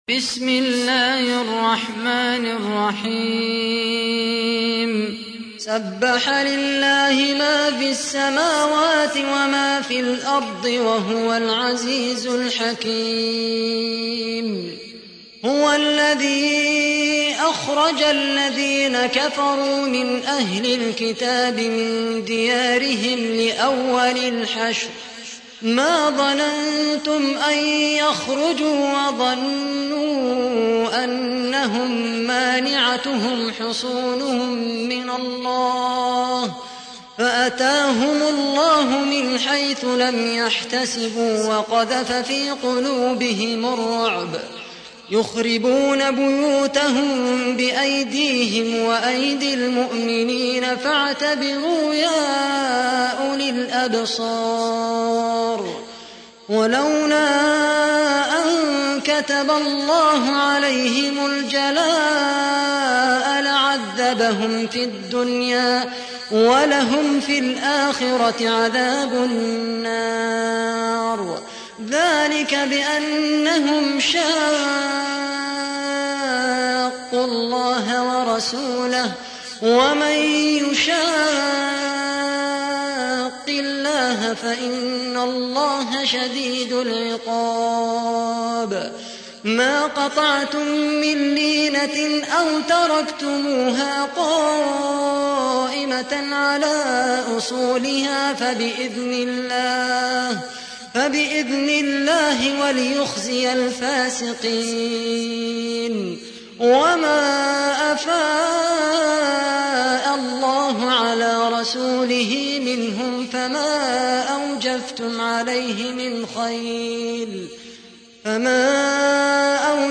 تحميل : 59. سورة الحشر / القارئ خالد القحطاني / القرآن الكريم / موقع يا حسين